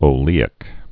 (ō-lēĭk)